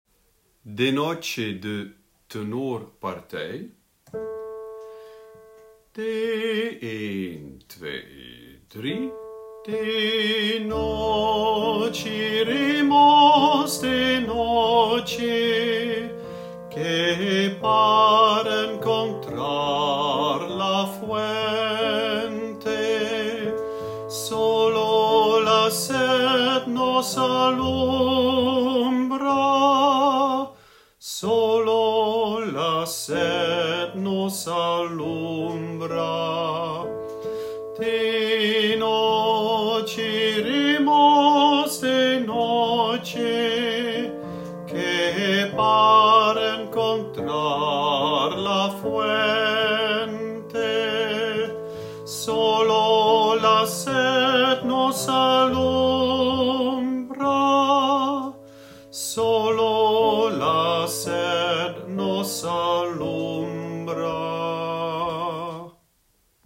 tenor
De-noche-iremos-tenor.mp3